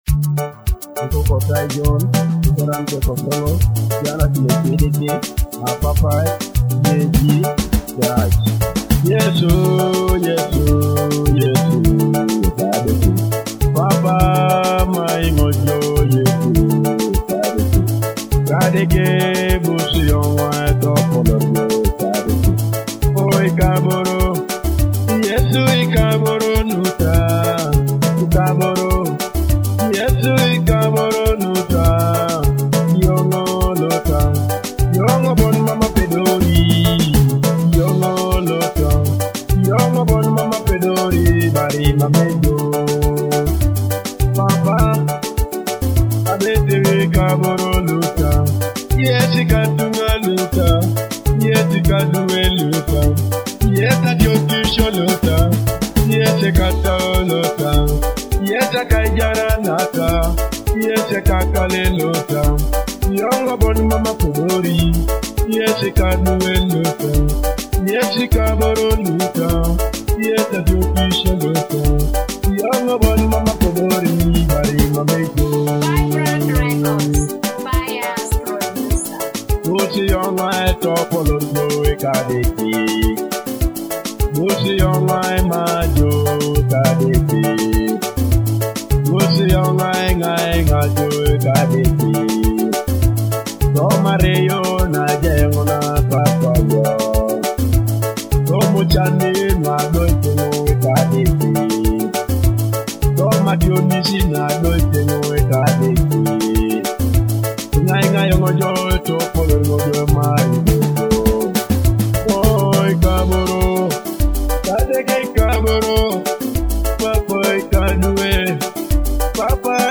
gospel hit